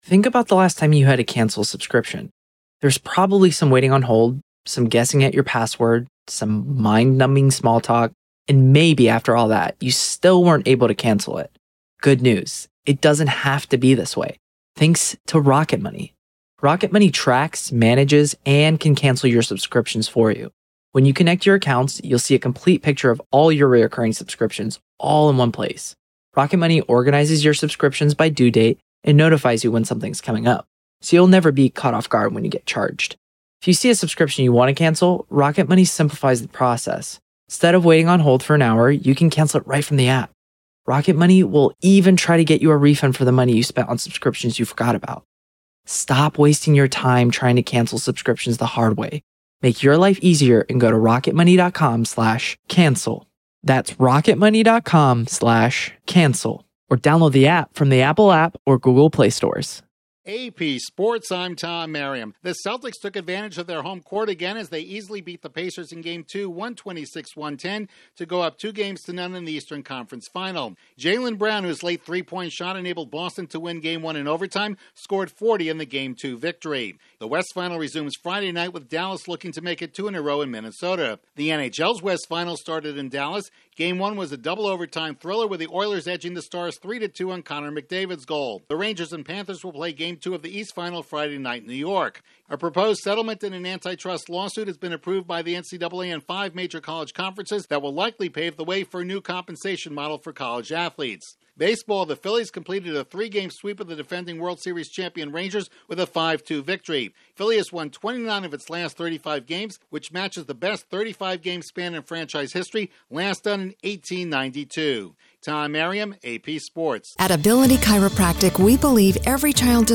The Celtics prevail at home again, the Oilers squeak out a double overtime victory, the NCAA moves closer to paying college athletes and the Phillies tie a franchise record set in 1892. Correspondent